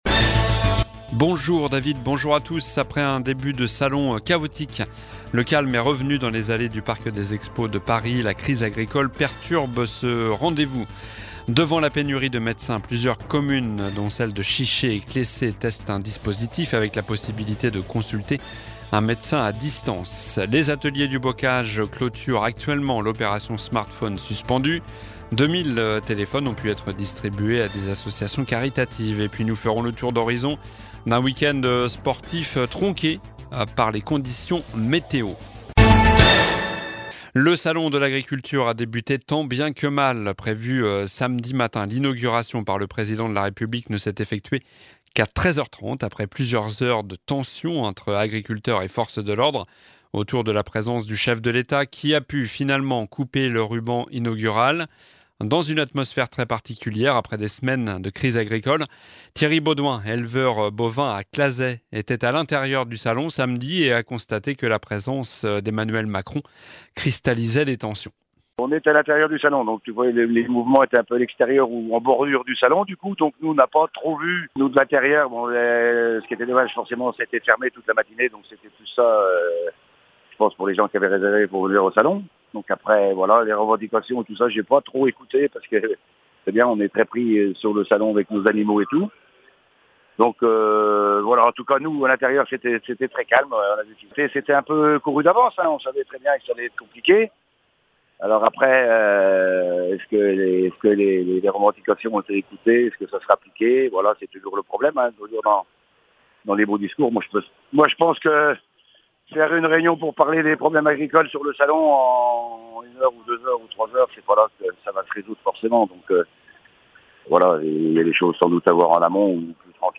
Journal du lundi 26 février (midi)